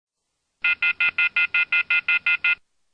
sosAlarm.mp3